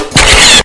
喀嚓.mp3